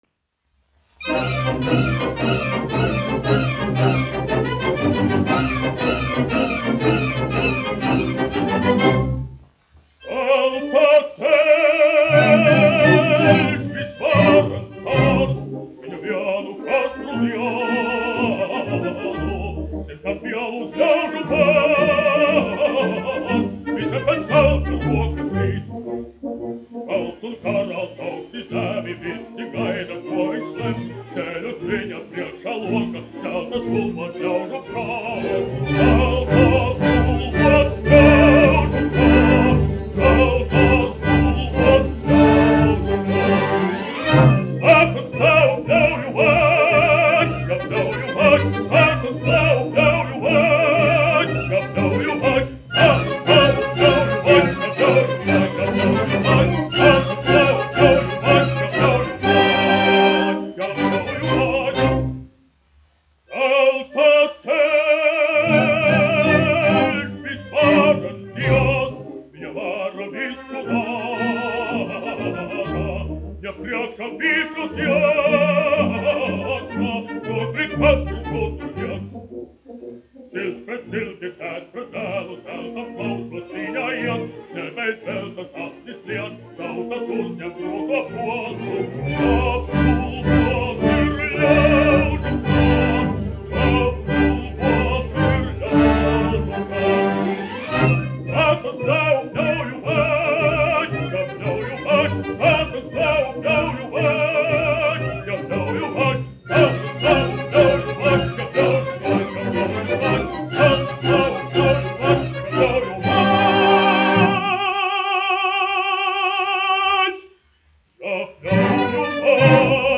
Kaktiņš, Ādolfs, 1885-1965, dziedātājs
1 skpl. : analogs, 78 apgr/min, mono ; 25 cm
Operas--Fragmenti
Skaņuplate